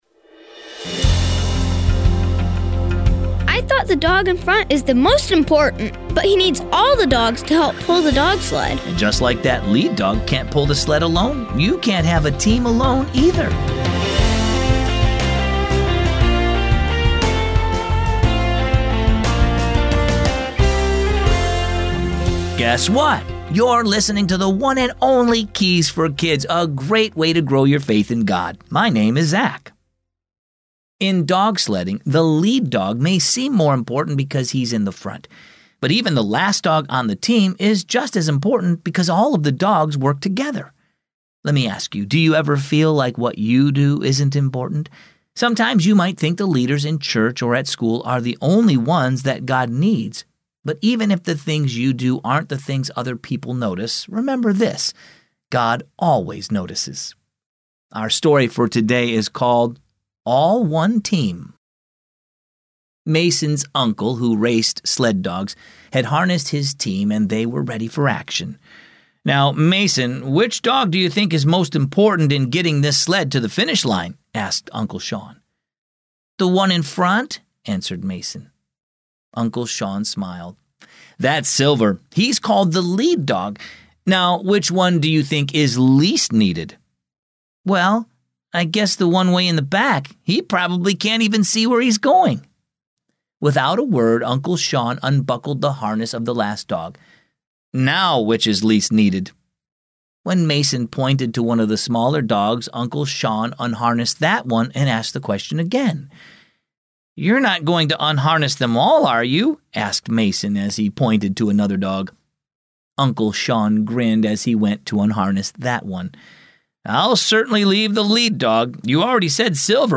Keys for Kids is a daily storytelling show based on the Keys for Kids children's devotional.